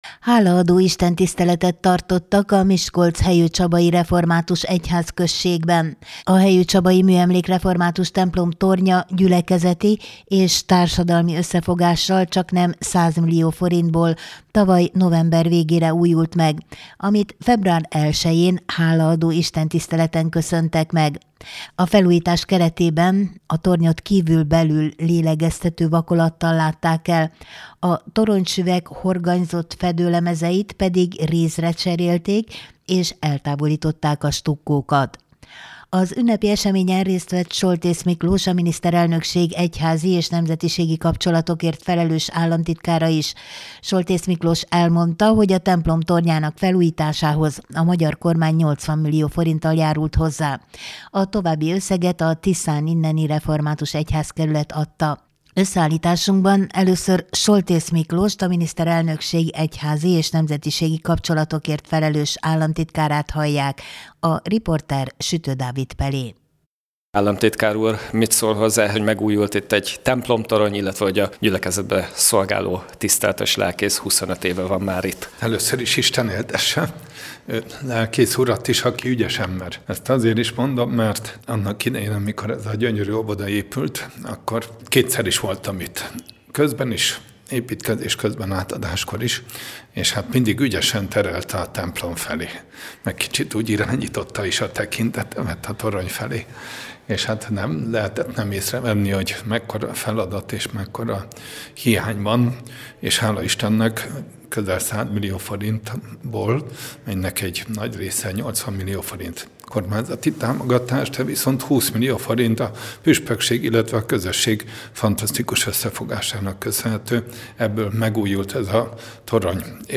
Hálaadó istentiszteletet tartottak a Miskolc-Hejőcsabai Református Egyházközségben
08-2_hejocsaba_halaado_istentisztelet.mp3